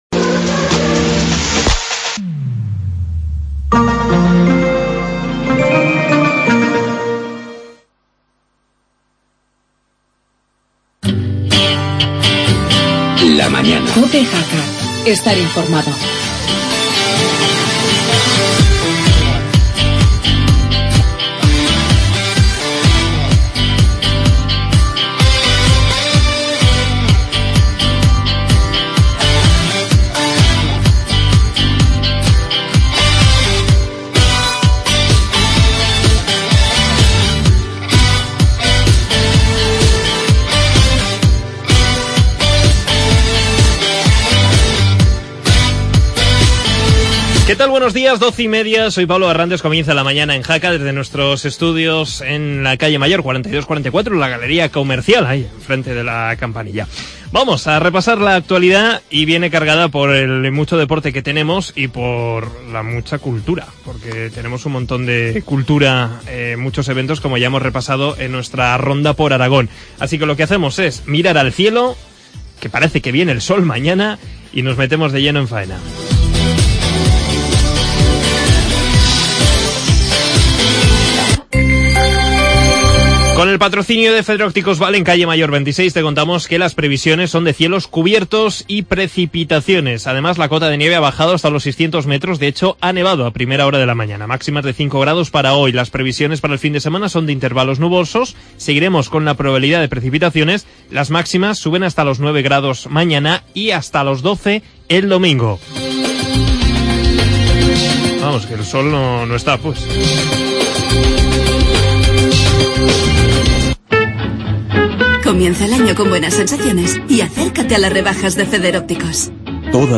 conexión con el alcalde de Canfrac y viajamos a Biescas por la clausura de...